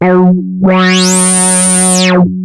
OSCAR  9 F#3.wav